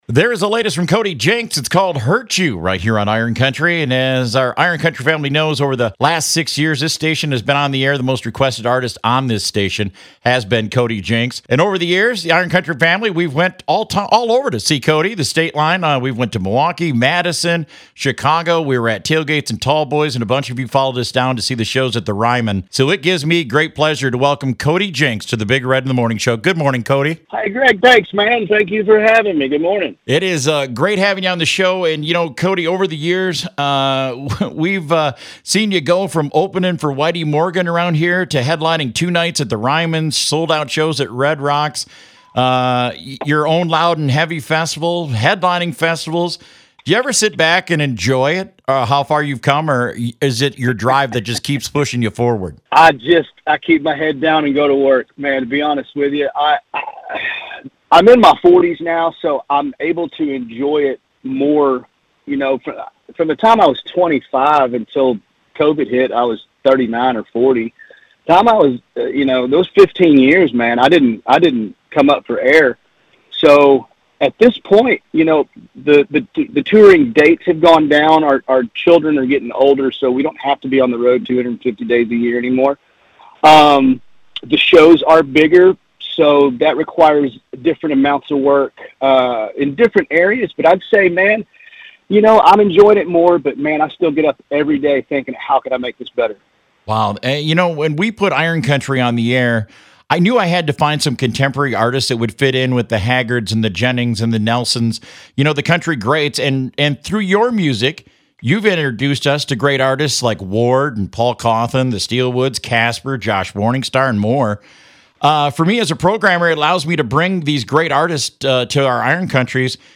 Cody Jinks Interview
CODY-JINKS-INTERVIEW.mp3